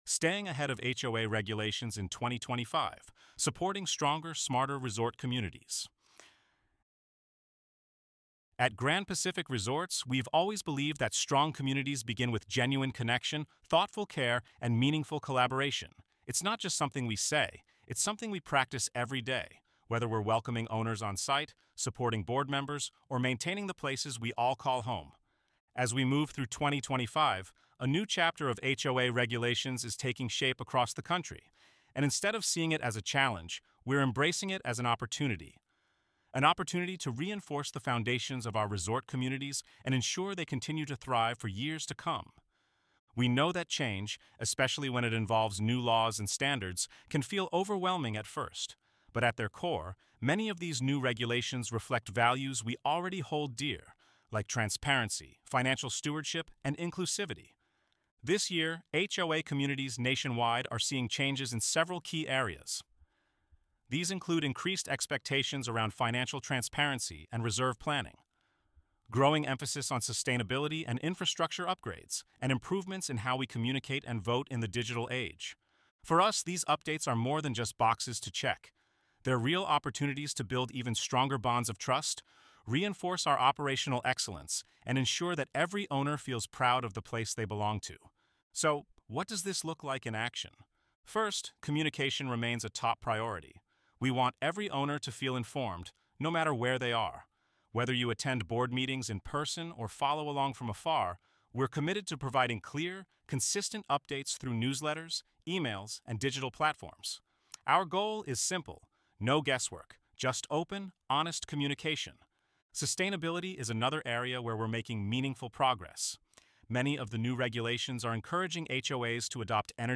ElevenLabs_Staying_Ahead_of_HOA_Regulations.mp3